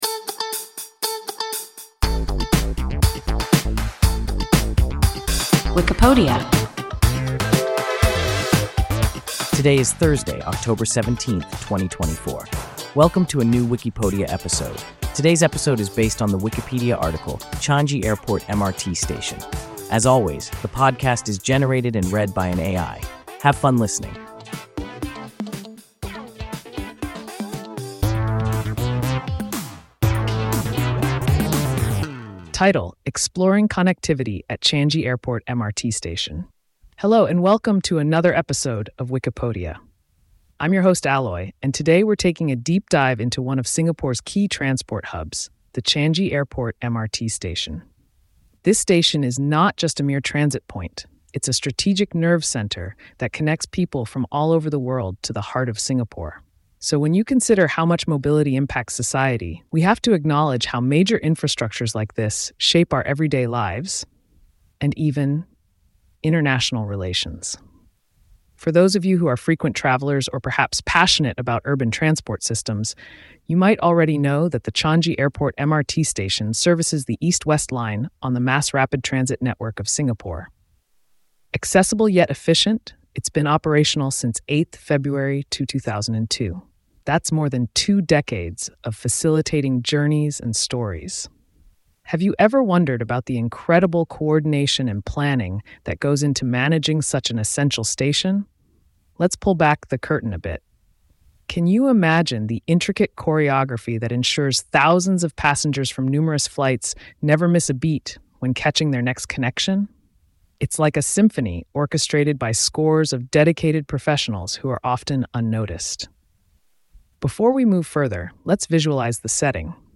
an AI podcast